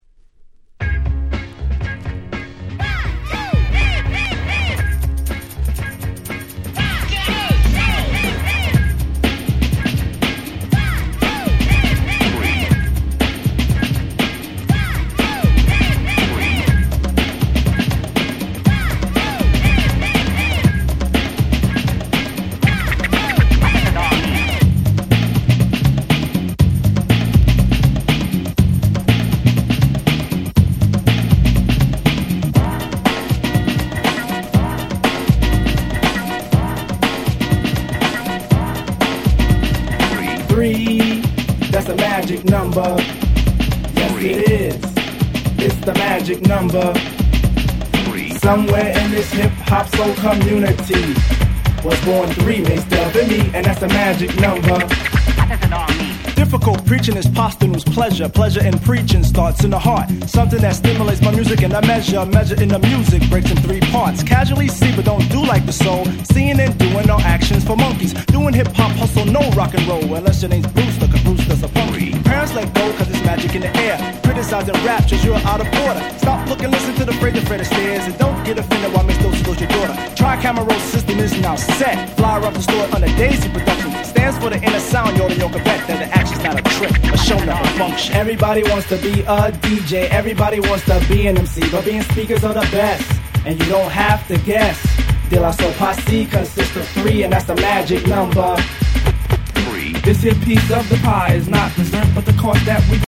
89' Smash Hit Hip Hop !!